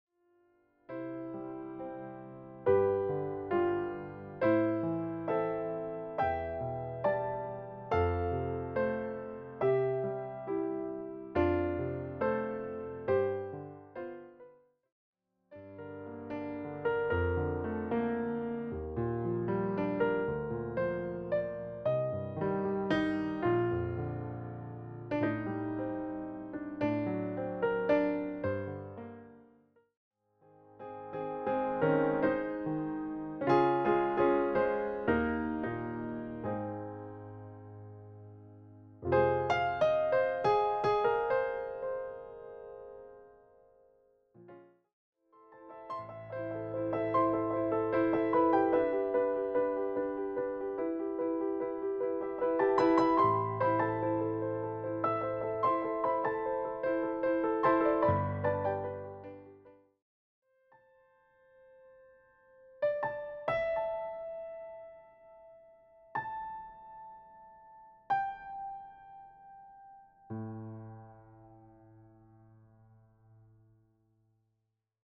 piano arrangements centered on winter and seasonal themes
calm, steady piano pieces